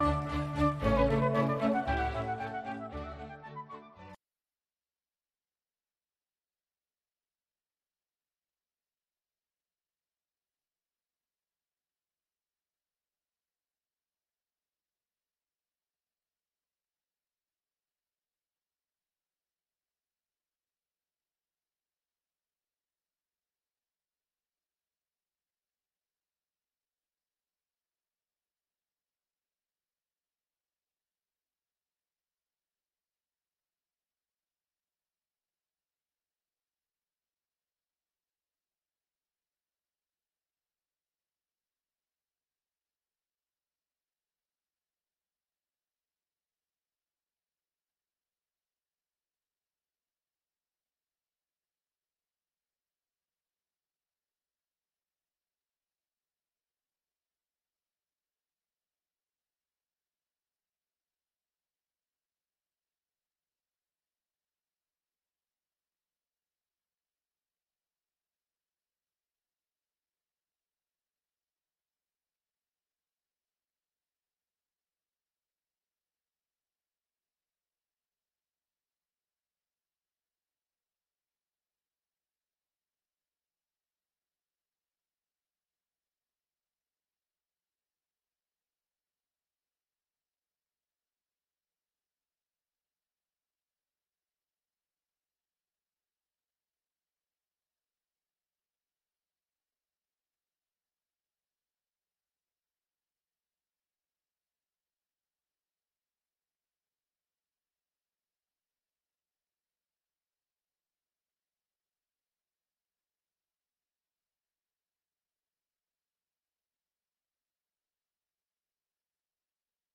VOA 한국어 아침 뉴스 프로그램 '워싱턴 뉴스 광장' 2021년 1월 7일 방송입니다. 북한은 5일 쏜 단거리 발사체가 극초음속 미사일이었다고 밝혔습니다. 토니 블링컨 미국 국무장관이 북한의 새해 첫 미사일 도발을 규탄했습니다. 1월 안보리 의장국인 노르웨이는 북한의 대량살상무기와 탄도미사일 개발에 우려를 표시했습니다.